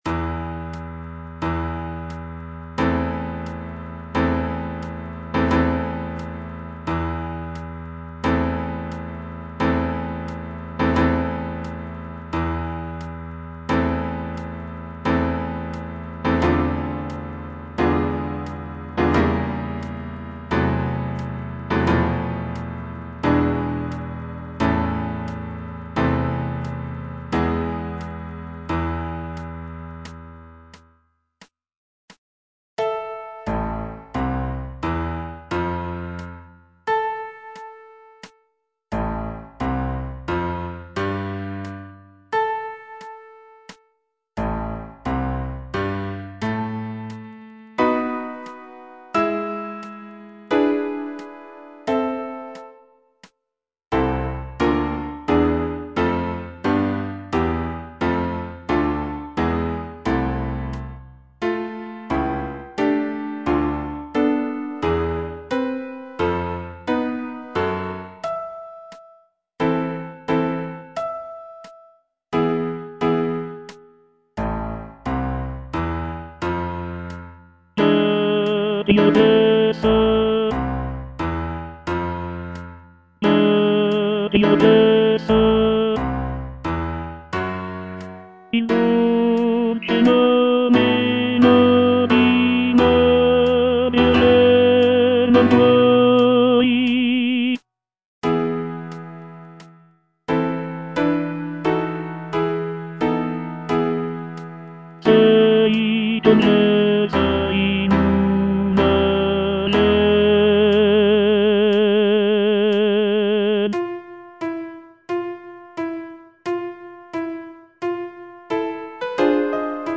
G. VERDI - CORO DI PROFUGHI SCOZZESI FROM "MACBETH" Tenor II (Voice with metronome) Ads stop: auto-stop
The piece features rich harmonies and powerful melodies that evoke a sense of sorrow and longing.